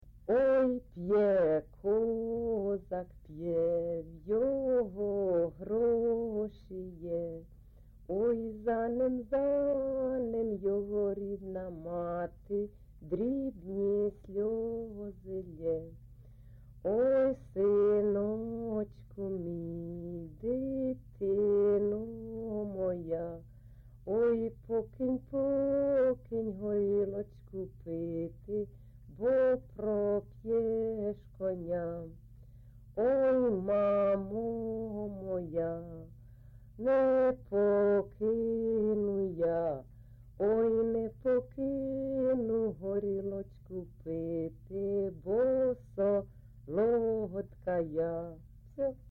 ЖанрПісні з особистого та родинного життя, Козацькі
Місце записус. Оленівка Волноваський район, Донецька обл., Україна, Слобожанщина